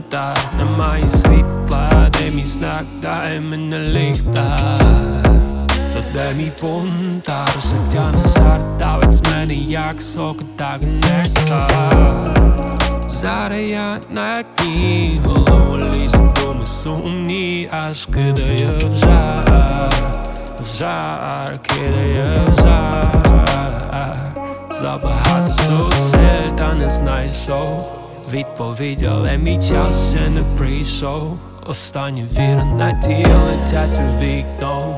Жанр: Украинские
# Contemporary R&B